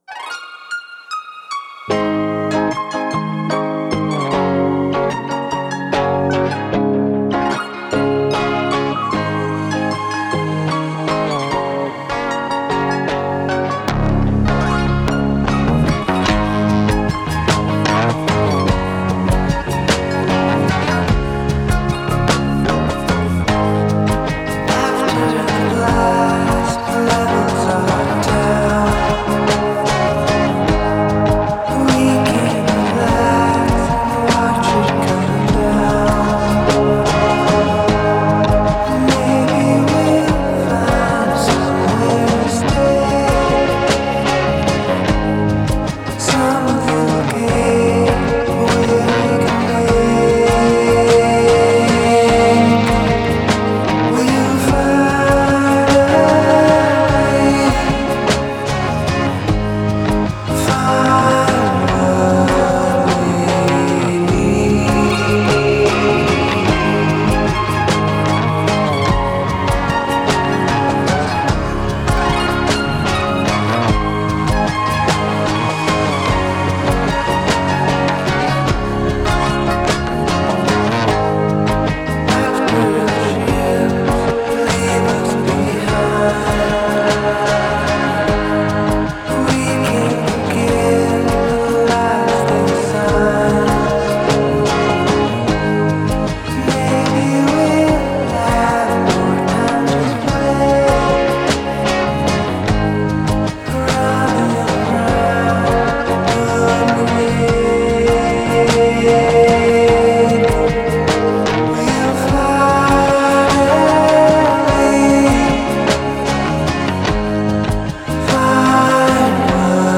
Жанр: Rock.